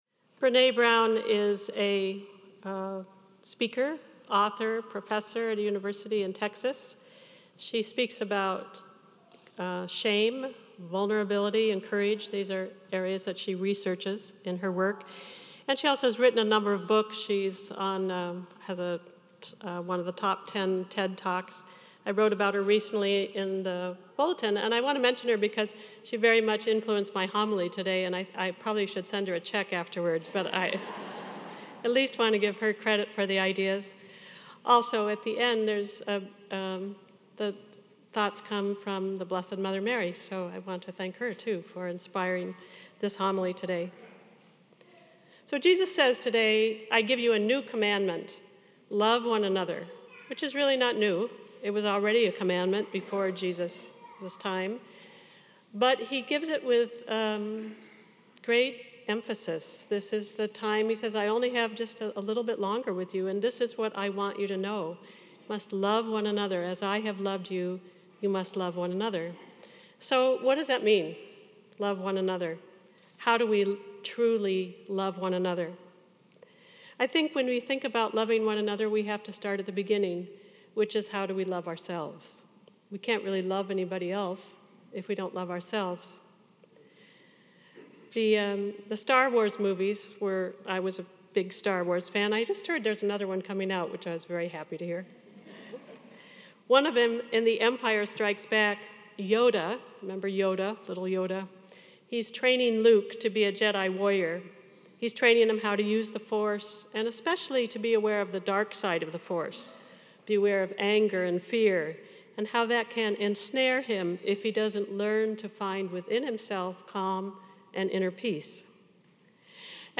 Homily Transcript Brené Brown is a speaker, author, and professor at a university in Texas.